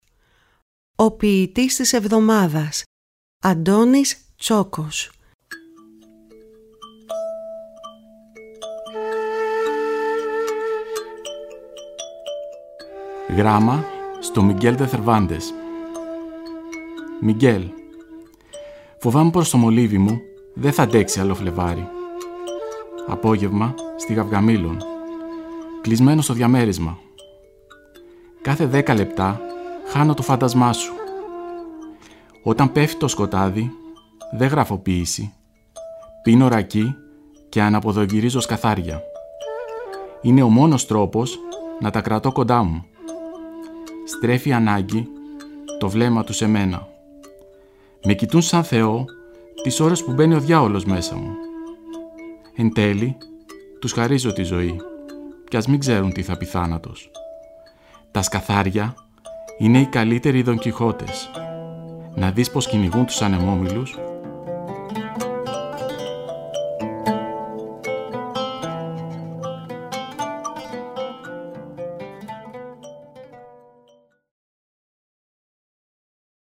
Οι ίδιοι οι ποιητές, καθώς και αγαπημένοι ηθοποιοί  επιμελούνται τις ραδιοφωνικές ερμηνείες.